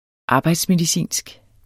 Udtale [ ˈɑːbɑjds- ]